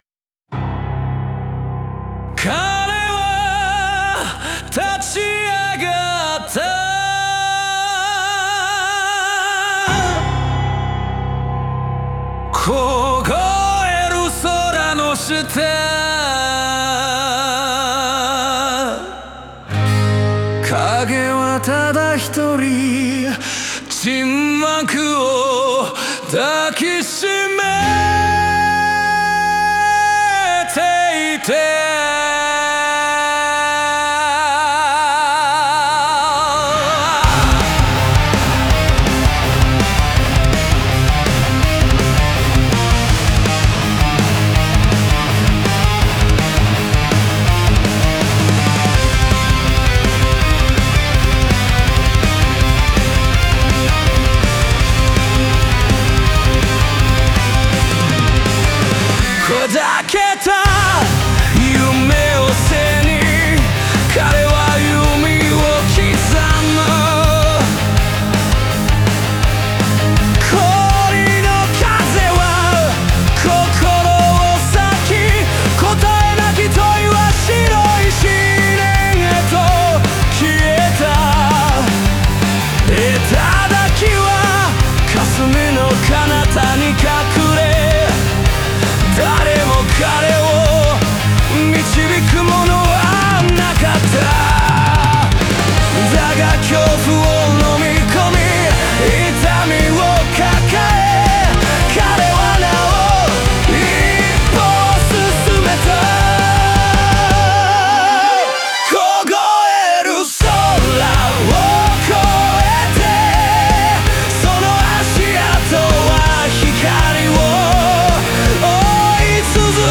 オリジナル曲♪
前半では静謐なアルペジオと低音の語りで孤独と試練を描き、中盤からは歪んだギターと疾走感あるドラムで挑戦と決意を強調。